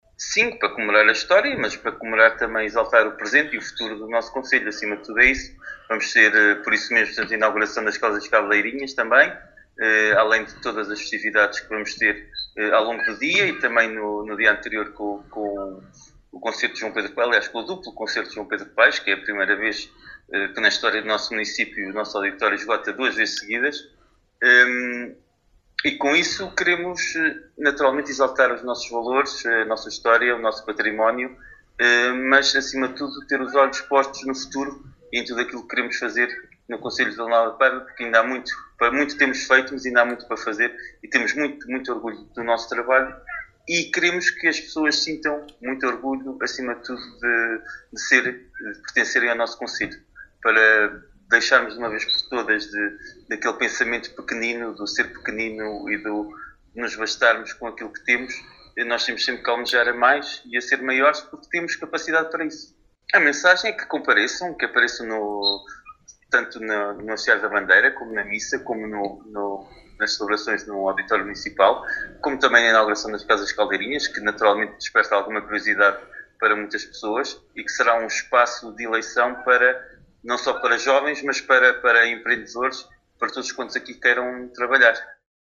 Paulo Marques, Presidente do Município de Vila Nova de Paiva, em declarações à Alive FM, fala do programa festivo do Dia do Município, “comemorar a história, exaltar o presente e o futuro do concelho…”. Aproveitando a ocasião, deixa o convite aos munícipes para se associarem ao Feriado Municipal.